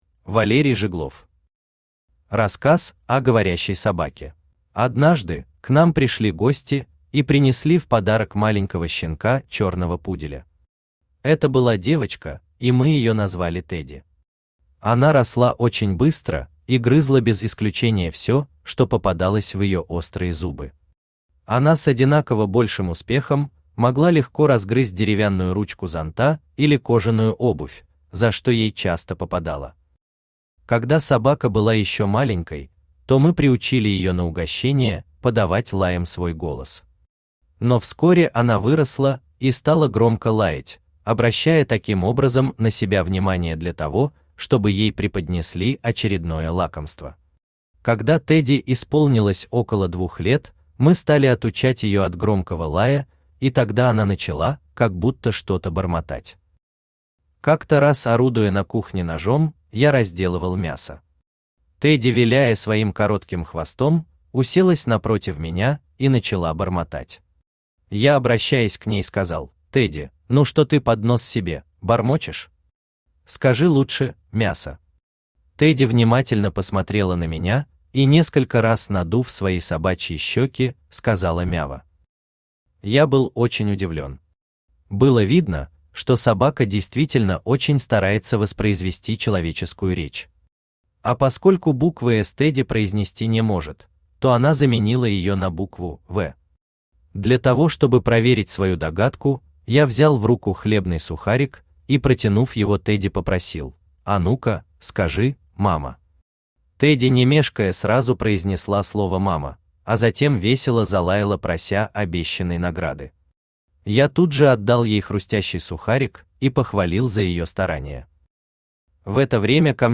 Aудиокнига Рассказ о говорящей собаке Автор Валерий Жиглов Читает аудиокнигу Авточтец ЛитРес.